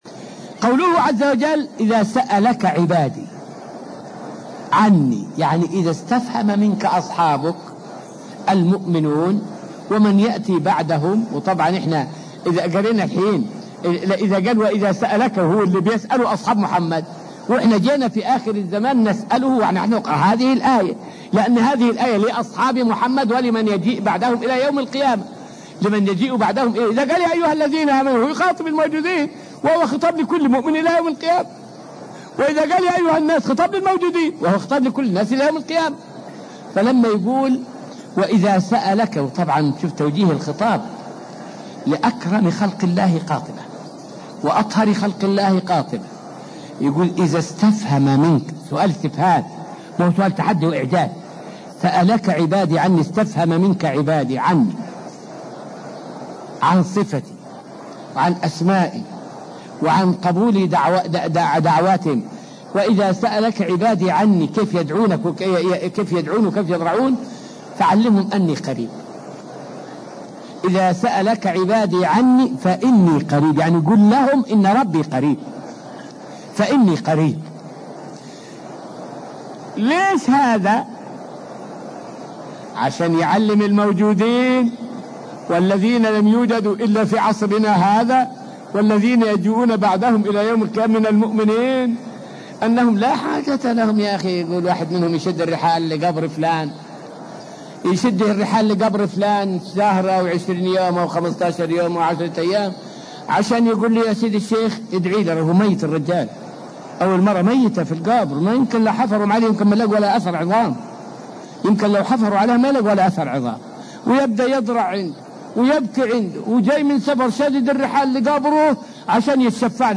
فائدة من الدرس الثالث والعشرون من دروس تفسير سورة البقرة والتي ألقيت في المسجد النبوي الشريف حول معنى قوله تعالى {إذا سألك عبادي عني}.